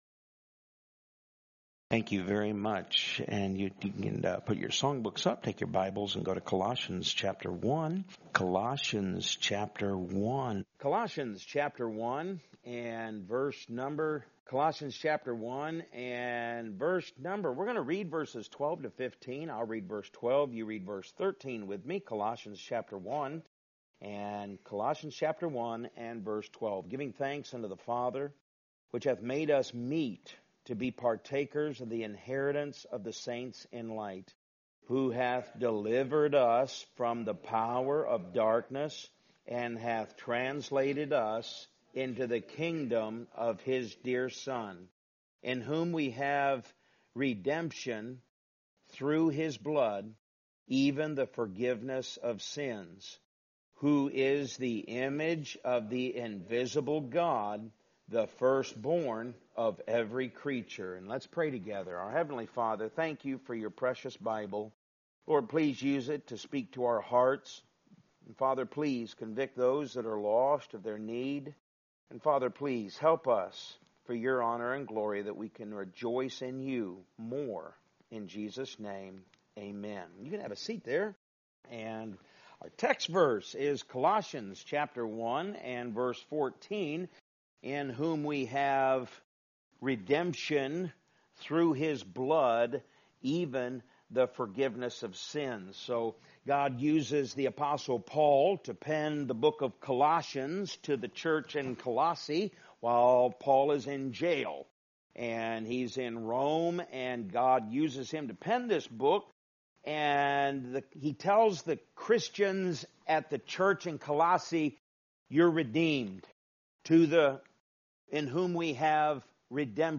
Podcast (sermon-podcast): Play in new window | Download